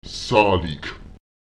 Lautsprecher zálek [Èsaùlek] rechnen, kalkulieren (mit Zahlen arbeiten)